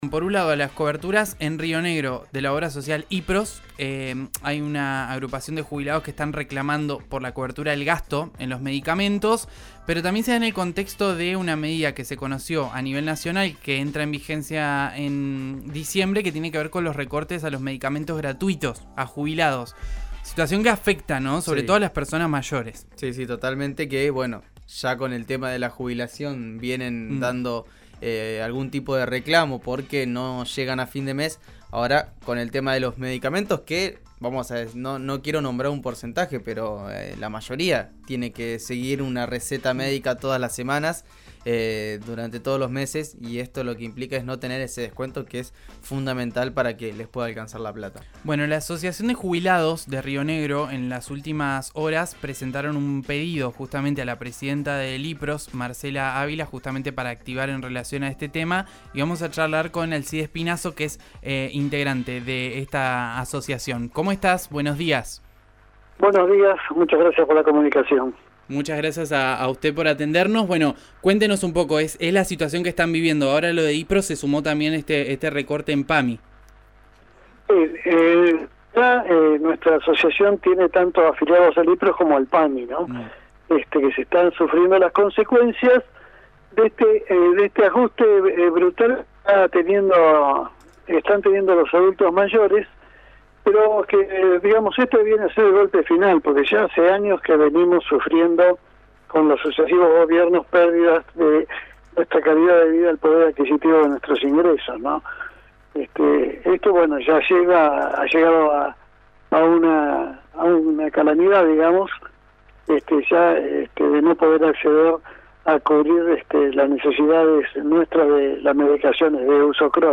integrante de la asociación, en diálogo con RÍO NEGRO RADIO.